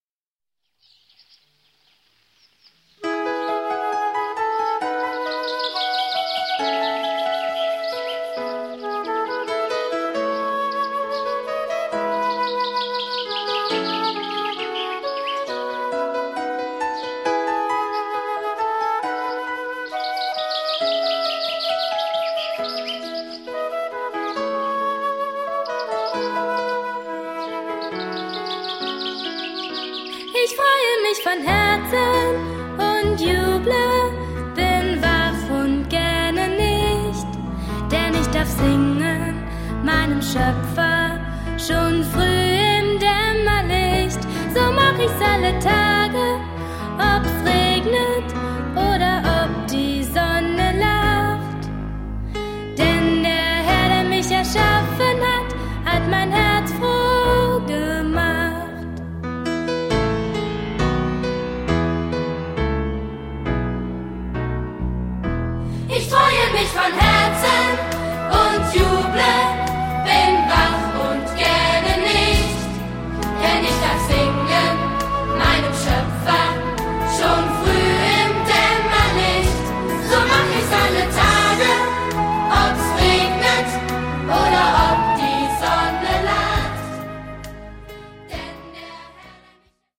Ein Musical für Kinder